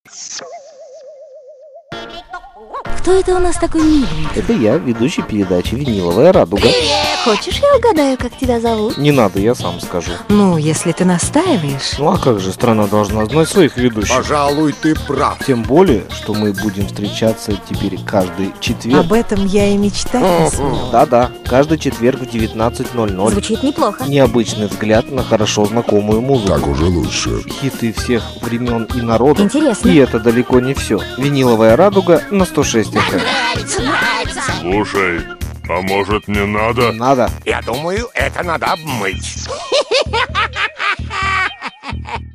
Ролики придумывал и записывал сам, на домашнем компьютере, лет 5-6 назад.
vrpromo1.mp3